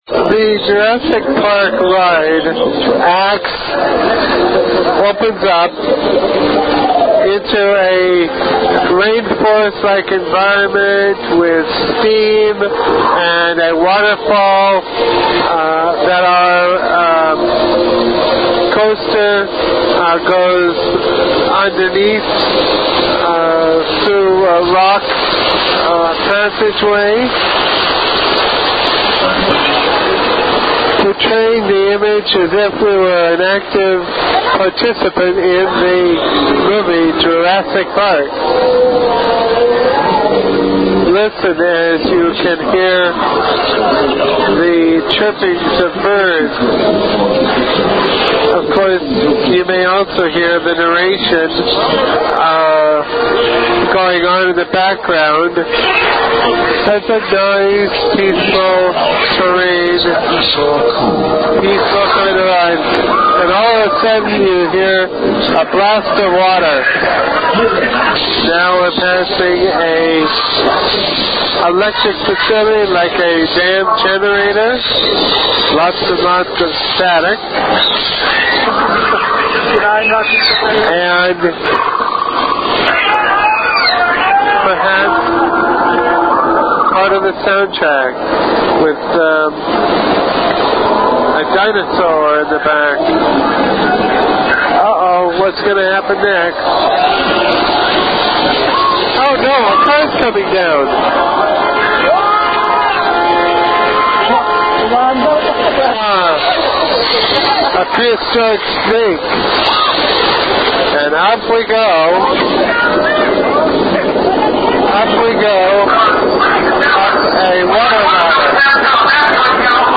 Jurassick Park Ride - Live Naration and Evaluatioin.mp3